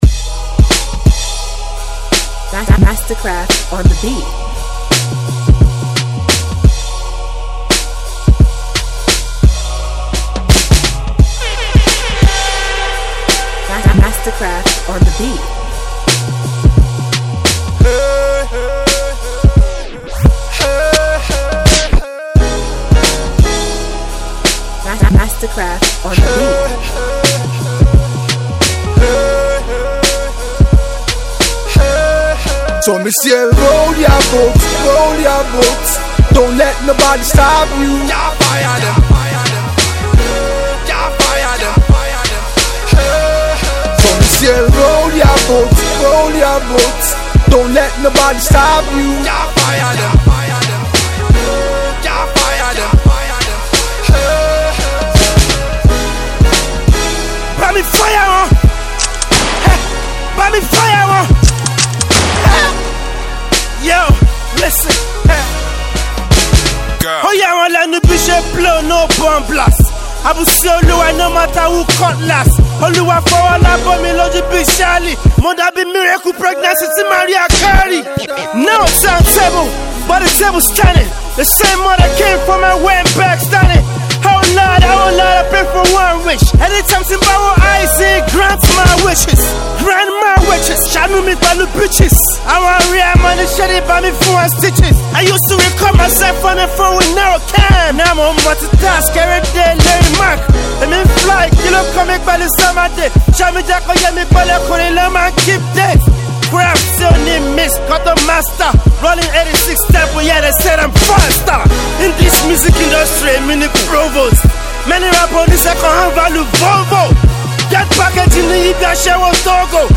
Hip-Hop
Street Banger